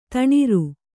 ♪ taṇiru